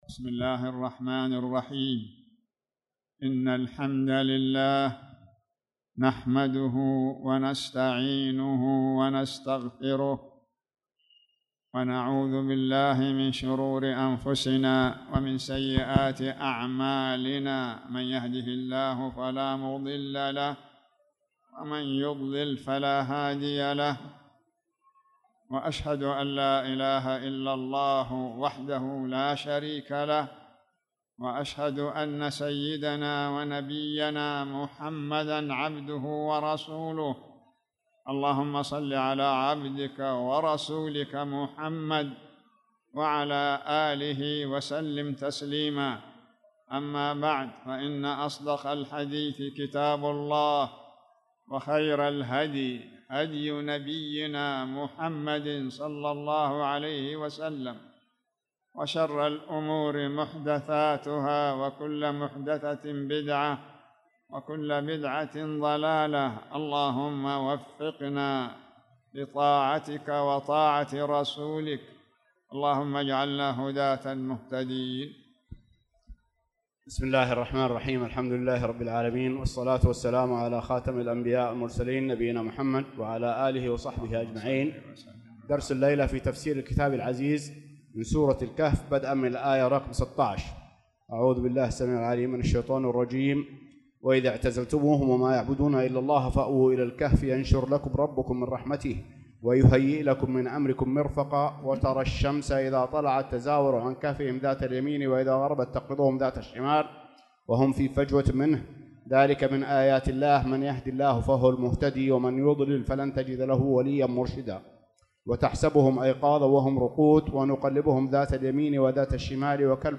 تاريخ النشر ٢٢ شوال ١٤٣٧ هـ المكان: المسجد الحرام الشيخ